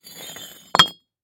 На этой странице собраны разнообразные звуки, связанные с кирпичами: от стука при строительстве до грохота падения.